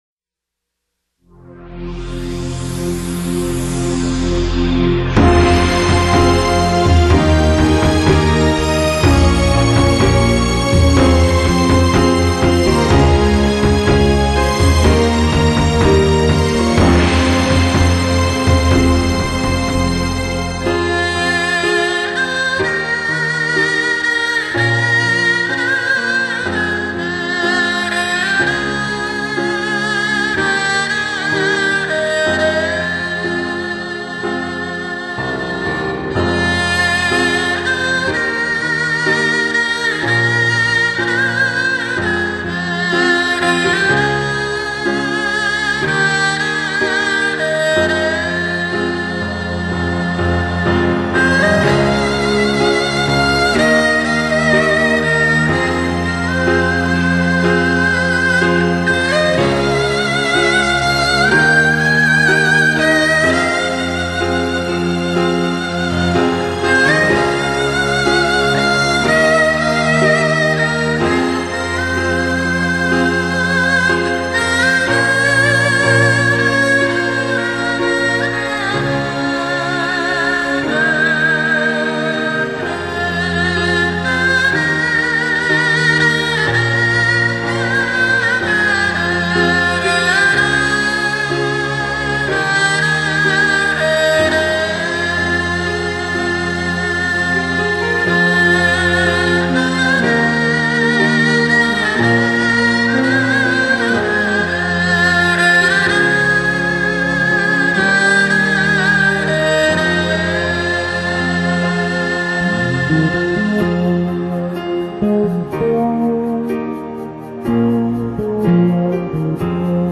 奚琴，朝鲜族拉弦乐器。
伤感、孤独、无奈、凄美，全部在曲子中体现。 高潮部分在前奏后20秒开始………………